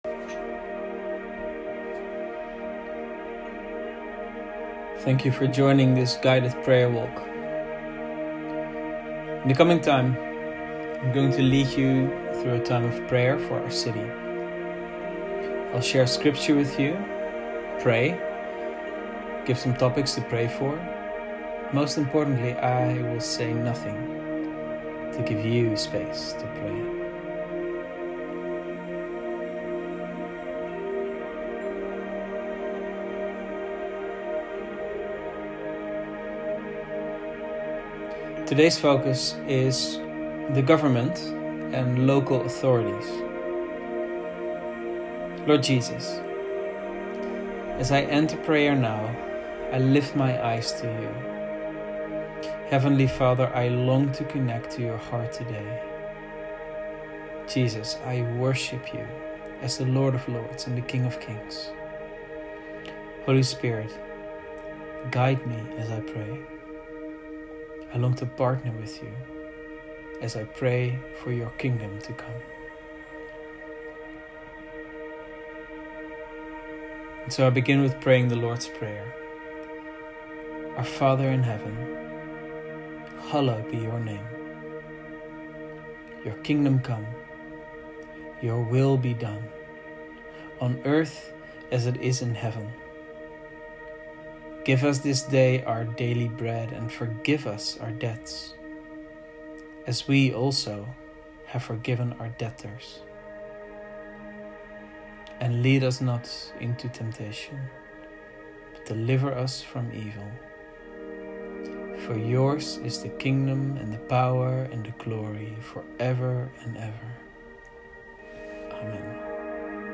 Guided Prayer Walks | Week 6 | Topic: Government & Local Authorities